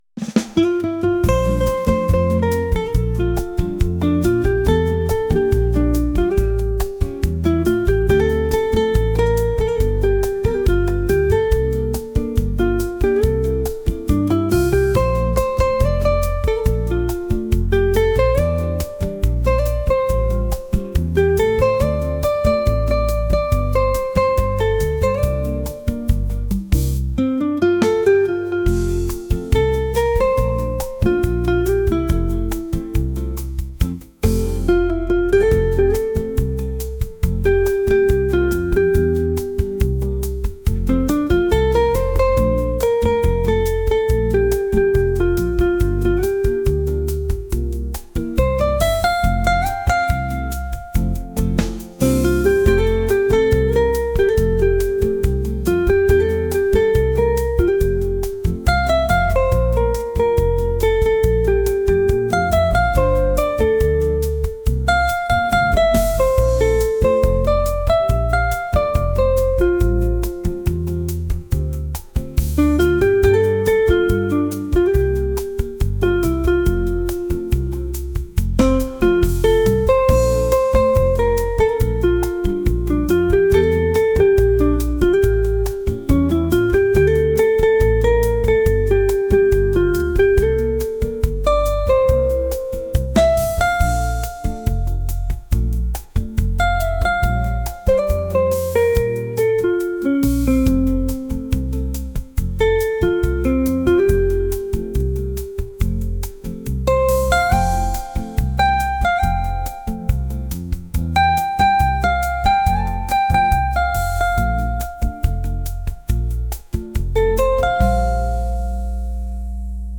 latin | smooth